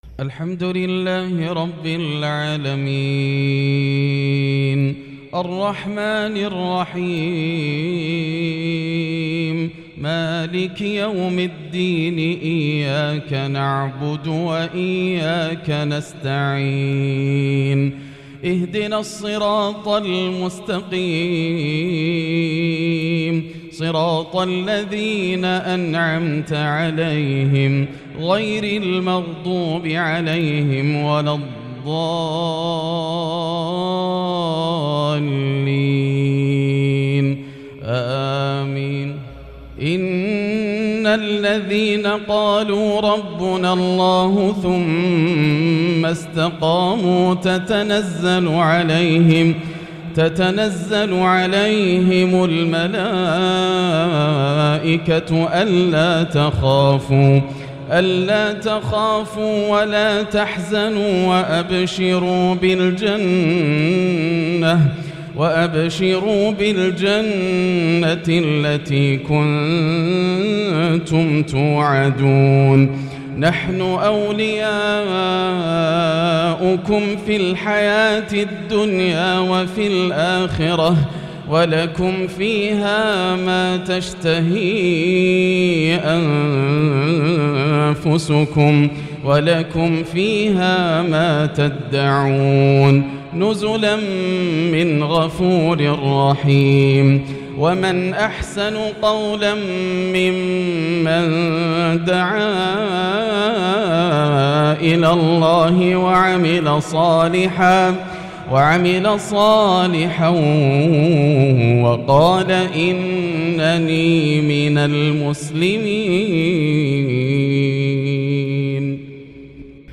مغرب الأربعاء 8-7-1443هـ من سورة فصلت | Maghrib Prayer from Surat Fusilat 9-2-2022 > 1443 🕋 > الفروض - تلاوات الحرمين